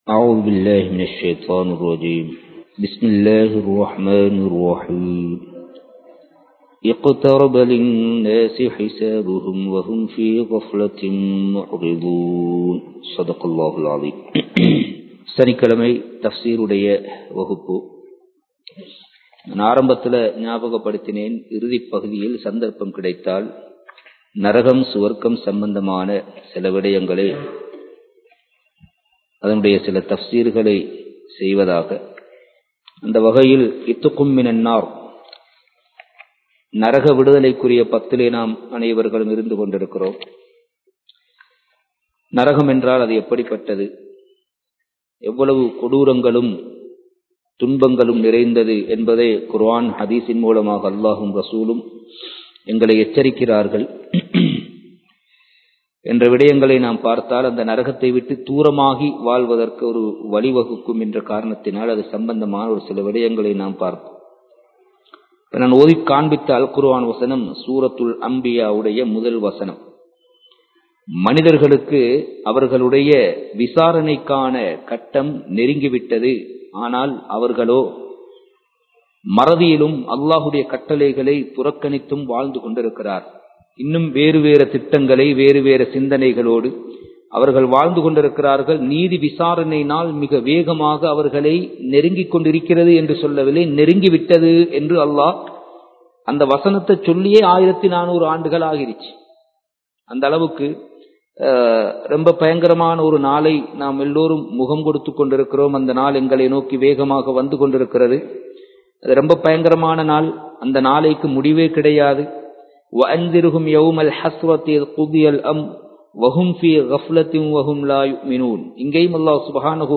நரகத்தின் கொடுமைகள் | Audio Bayans | All Ceylon Muslim Youth Community | Addalaichenai
Kandy, Kattukela Jumua Masjith